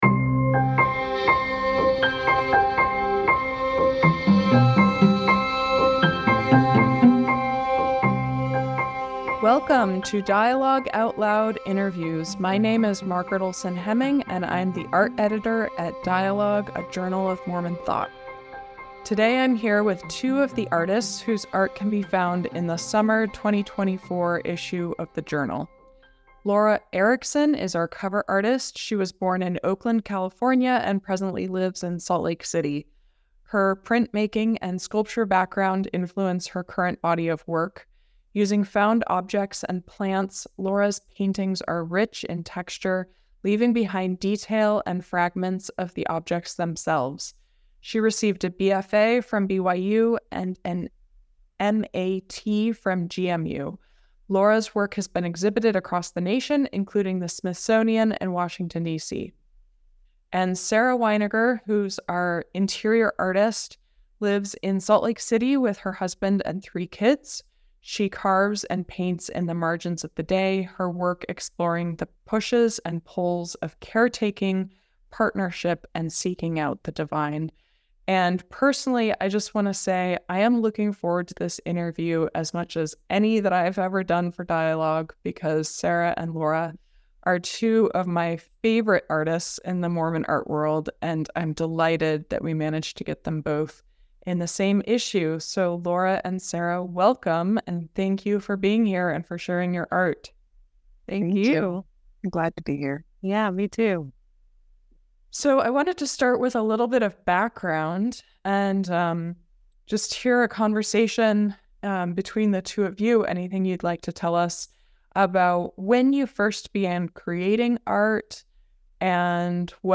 Listen now on the Dialogue Podcast Network.